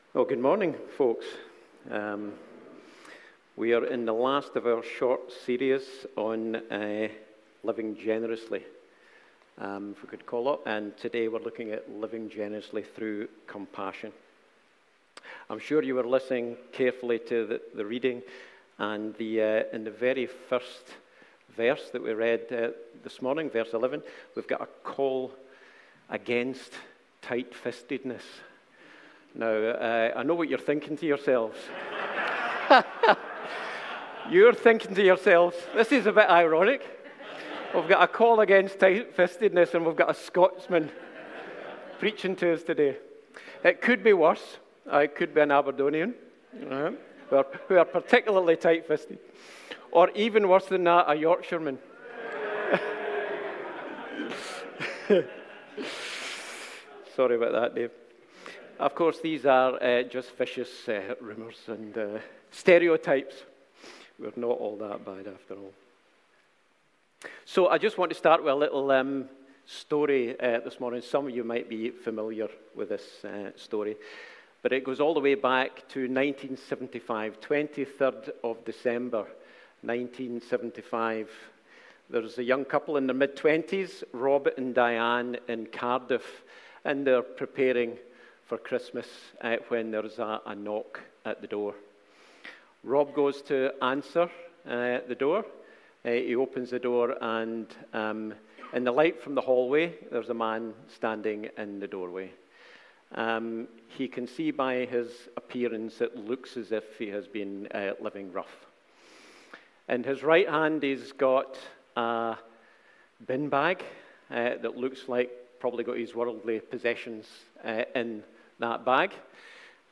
Passage: Deuteronomy 15:7-11 Service Type: Sunday Morning